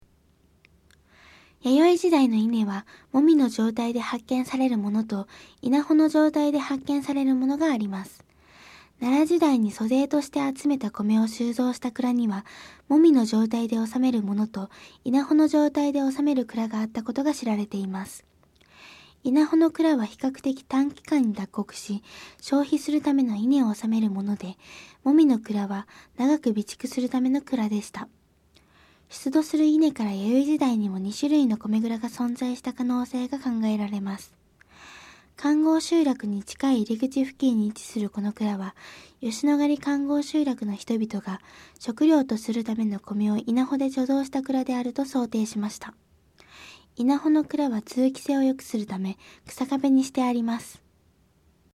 音声ガイド 前のページ 次のページ ケータイガイドトップへ (C)YOSHINOGARI HISTORICAL PARK